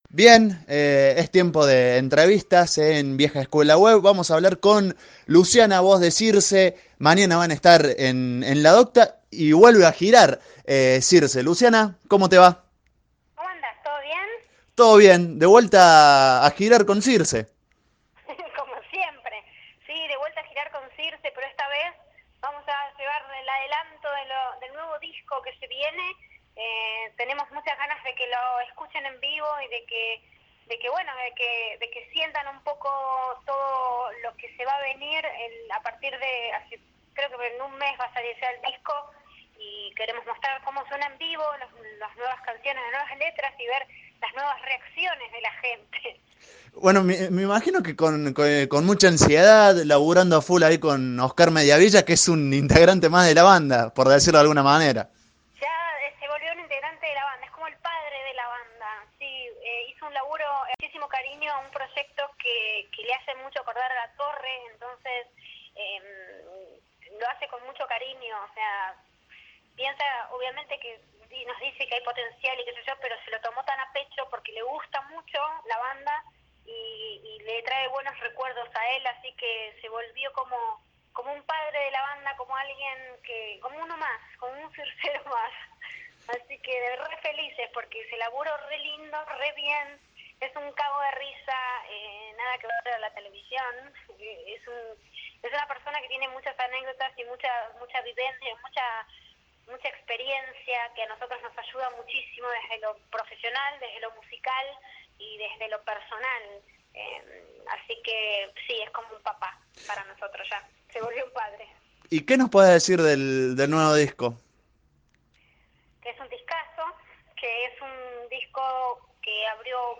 Entrevista-Cirse.mp3